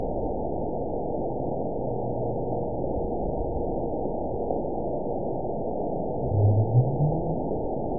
event 920408 date 03/23/24 time 23:30:30 GMT (1 year, 1 month ago) score 9.44 location TSS-AB02 detected by nrw target species NRW annotations +NRW Spectrogram: Frequency (kHz) vs. Time (s) audio not available .wav